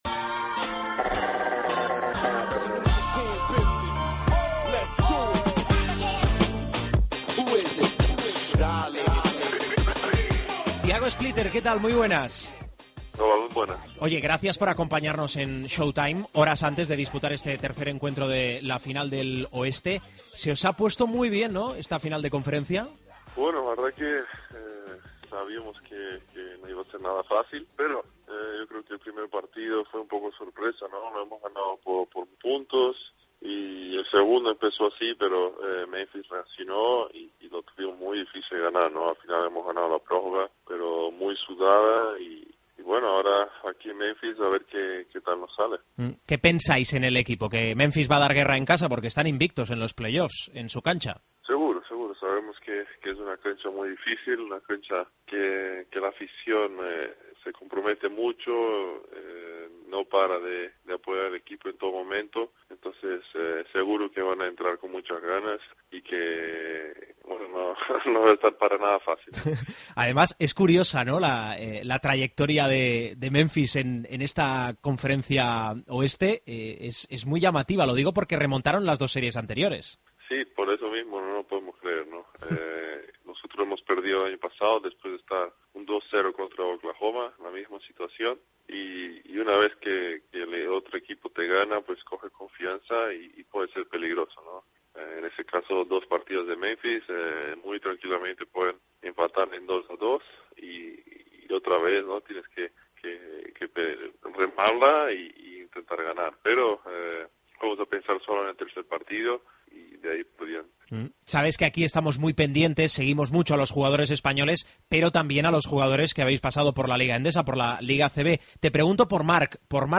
Entrevista a Tiago Splitter